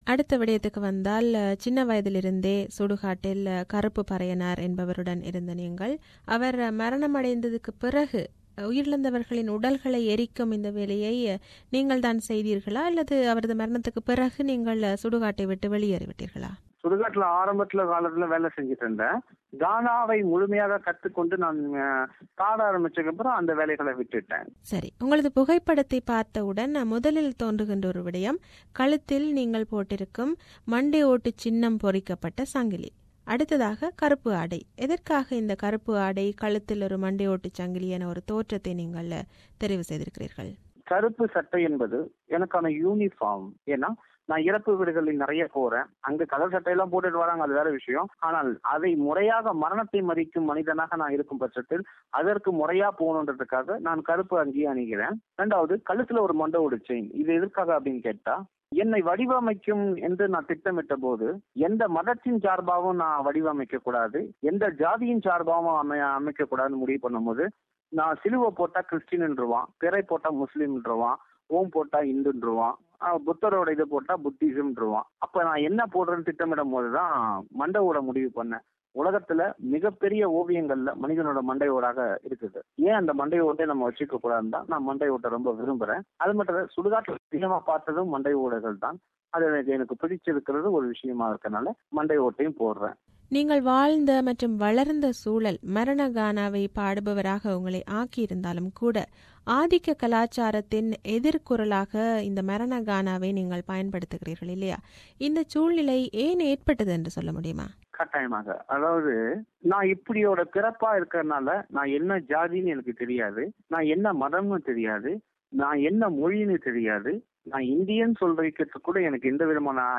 He opens up and shares his life story. This is the final part of the interview